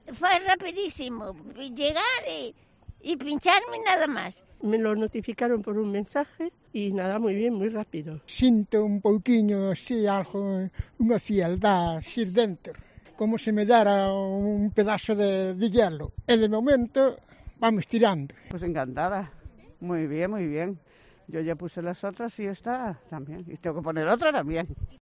Testimonios de mayores de 70 años tras recibir la dosis de refuerzo contra la covid-10 en Pontevedra